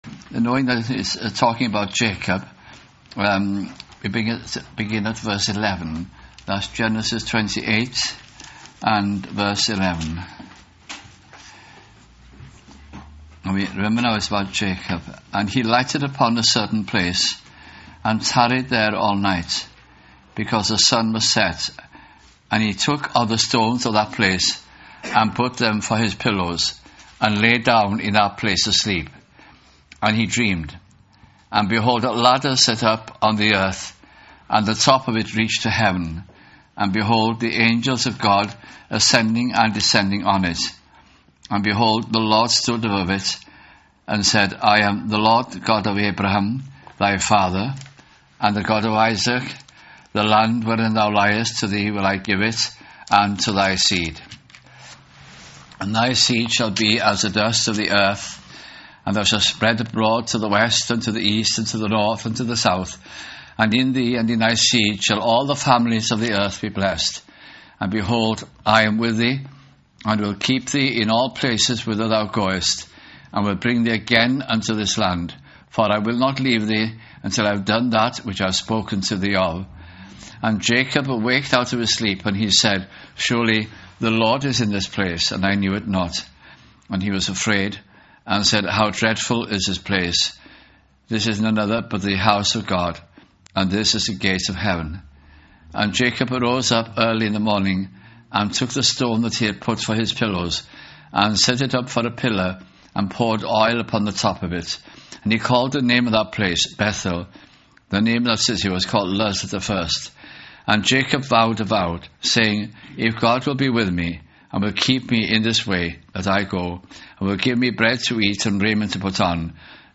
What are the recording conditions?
» Hebrews » Bible Study Series 2006 - 2008 » at Tabernacle Cardiff in the Heath Citizens Hall